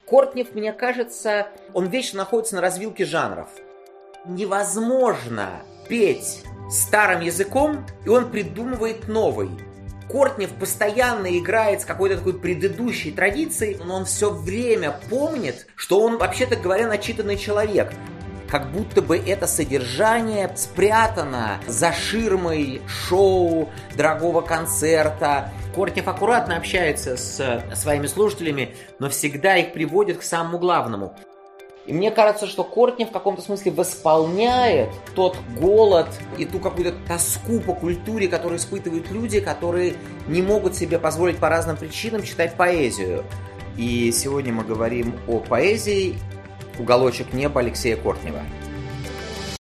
Аудиокнига Уголочек неба Алексея Кортнева | Библиотека аудиокниг